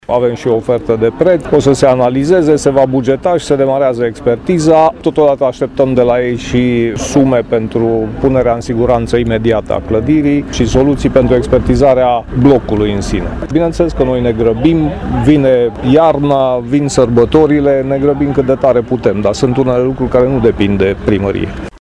Costurile lucrării respective vor fi acoperite de primărie, a precizat viceprimarul Laszlo Barabas: